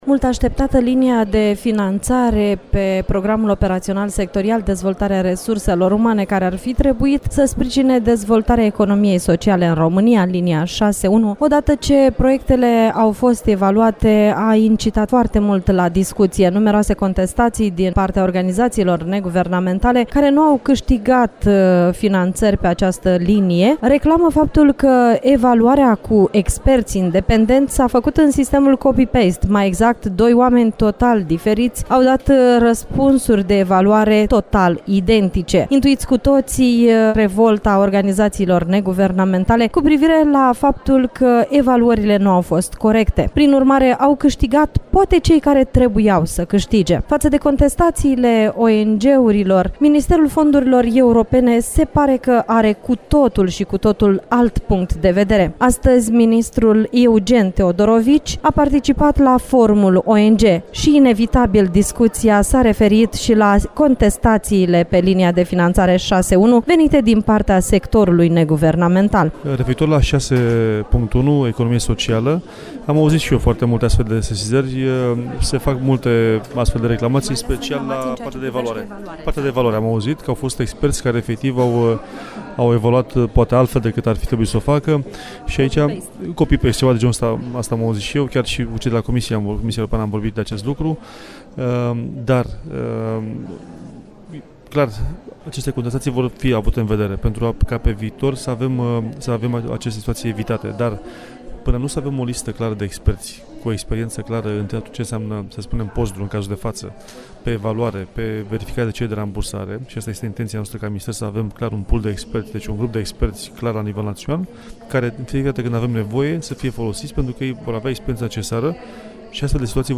Astăzi, la Forumul ONG, eveniment care se desfăşoară la Bucureşti pentru 2 zile, ministrul fondurilor europene Eugen Teodorovici a răspuns nemulţumirii ONG-urilor, cu privire la evaluarea proiectelor POSRDU pe linia de finanțare 6.1. economie socială.
Astăzi, la Semnal critic, ascultaţi integral declaraţia ministrului Teodorovici acordată în EXCLUSIVITATE pentru Radio Iaşi.